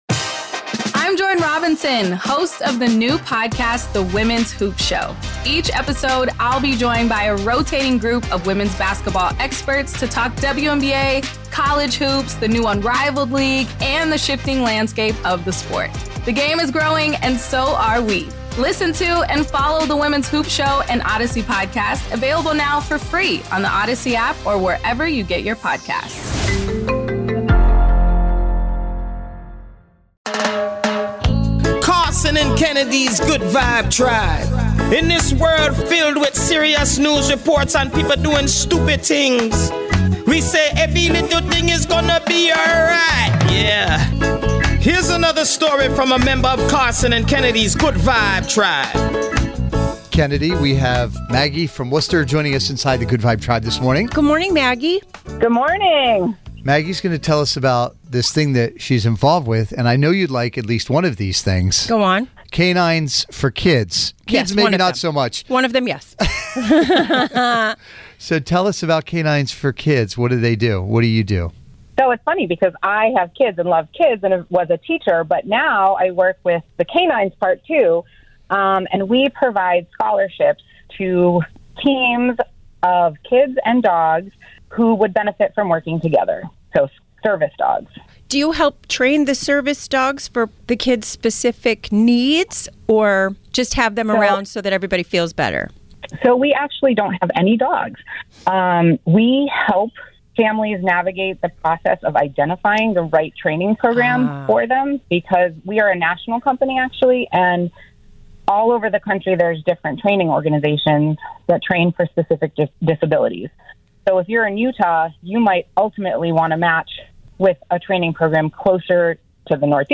The show is fast paced and will have you laughing until it hurts one minute and then wiping tears away from your eyes the next.